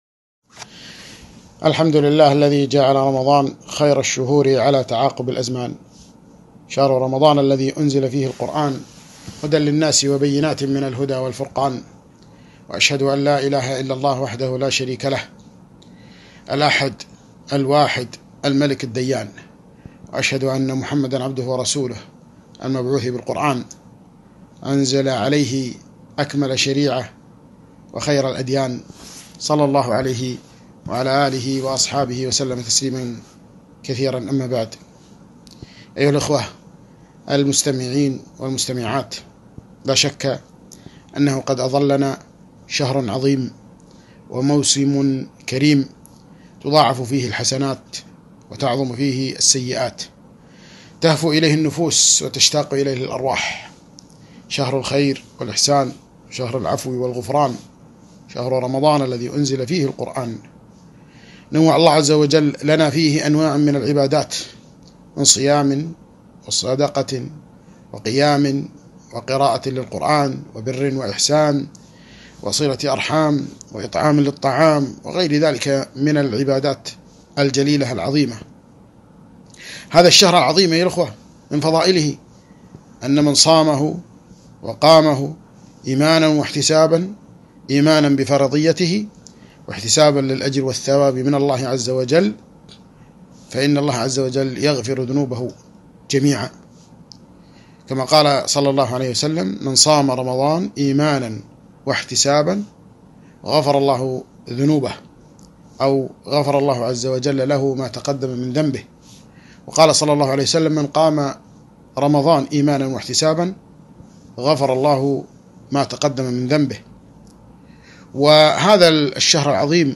محاضرة - وصايا مهمة للصائمين - دروس الكويت